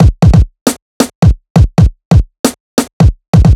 Ux Break 135.wav